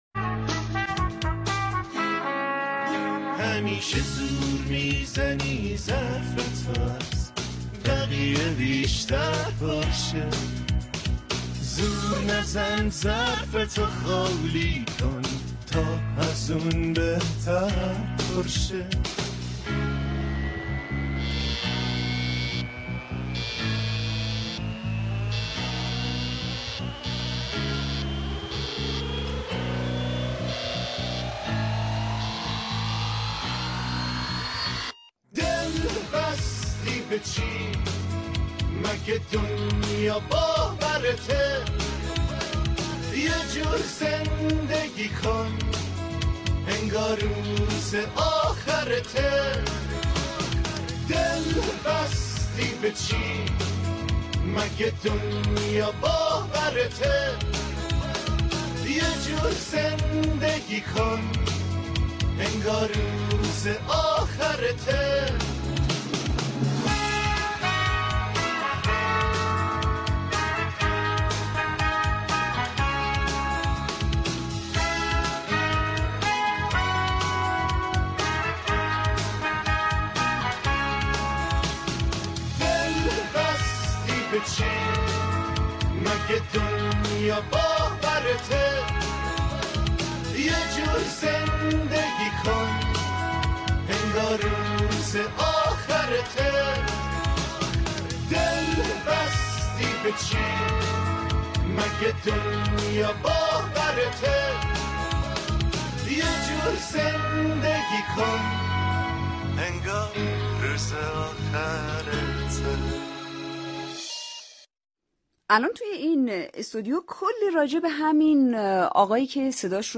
دانلود مصاحبه سیامک عباسی و سینا حجازی با رادیو جوان :: سیامک عباسی - وبلاگ هواداران
این گفتگو در تاریخ پنج شنبه ۲۳ شهریور ۱۳۹۱ از رادیو جوان ایران پخش شده است.